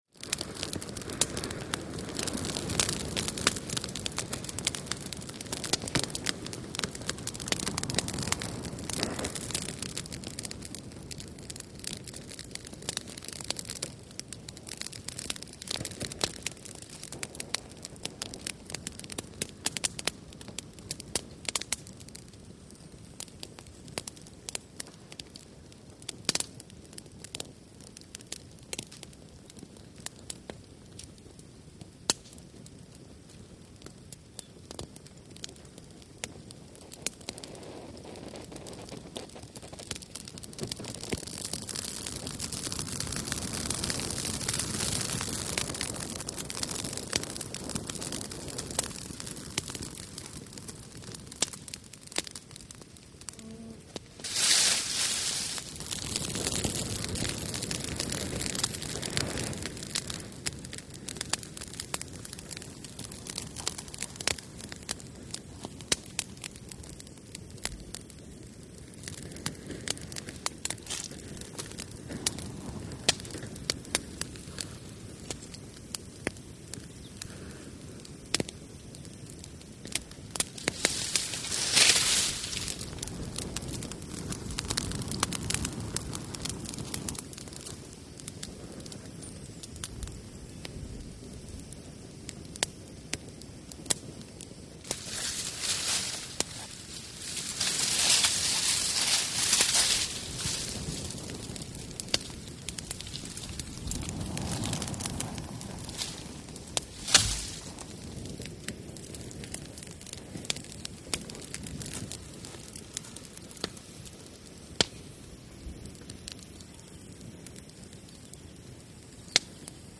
Campfire|Exterior
CampFireCracksPops PE700601
FIRE CAMP FIRE: EXT: Wind fanning fire, leaves thrown on fire, low burning fire, close up cracks & pops, leaf gather at end, fire intensity increase.. Fire Burn.